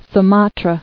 [Su·ma·tra]